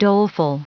Prononciation du mot doleful en anglais (fichier audio)
Prononciation du mot : doleful